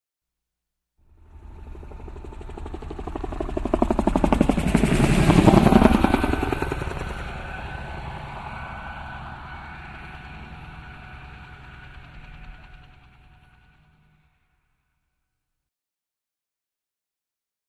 Звуки вертолёта
Шум винта вертолета